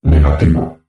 voz nș 0145